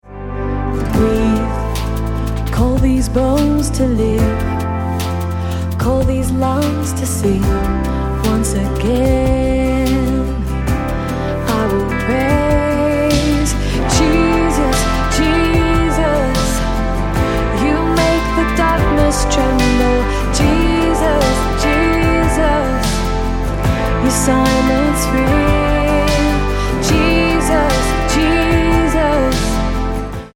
Am